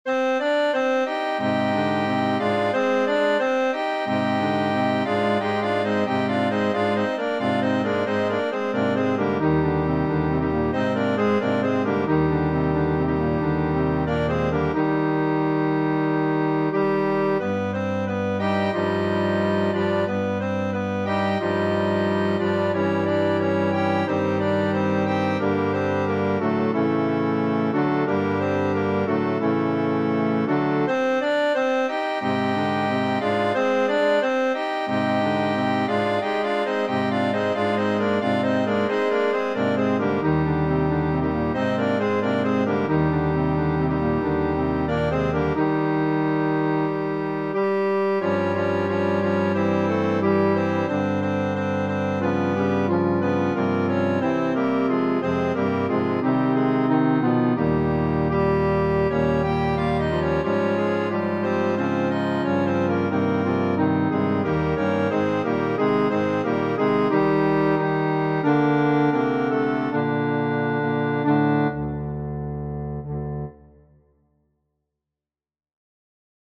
FF:HV_15b Collegium male choir